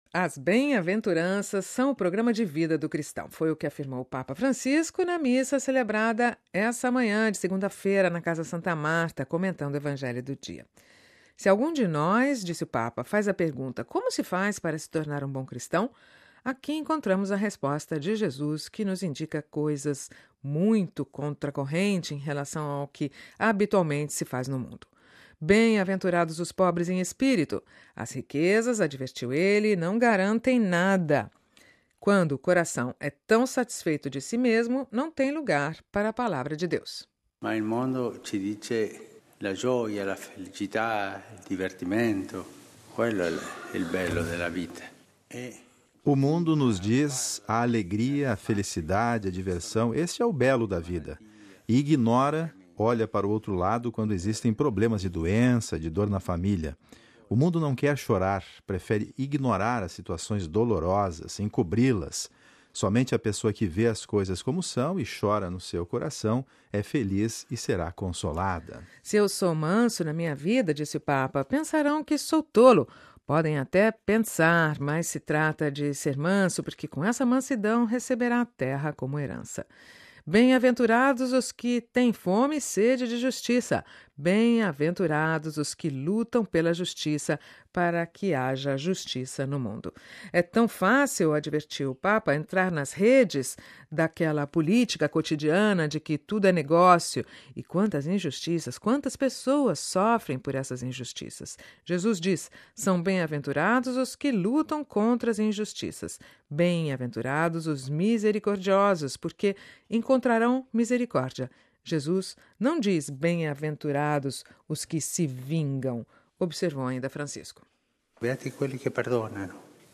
Cidade do Vaticano (RV) – As Bem-aventuranças são o programa de vida do cristão. Foi o que afirmou o Papa Francisco na missa nesta manhã na Casa Santa Marta, comentando o Evangelho do dia.